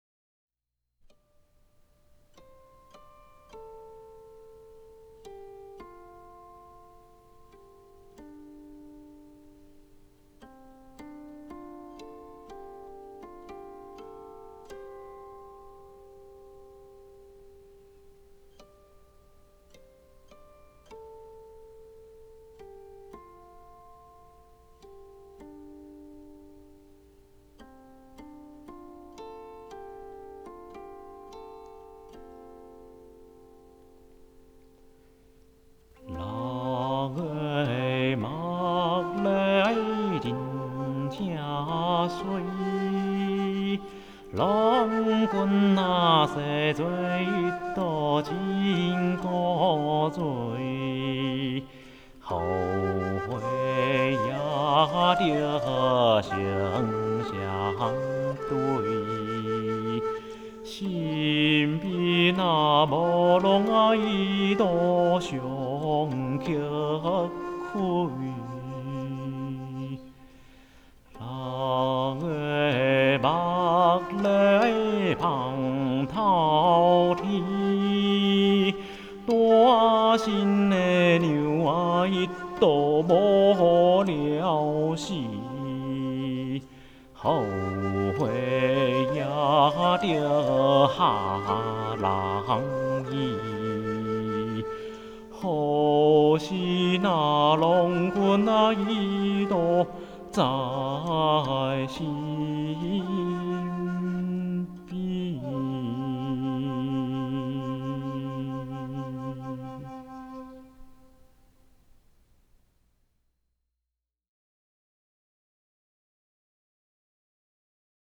簫
全球首張琴人自彈自唱 重現琴曲襌雅至性風貌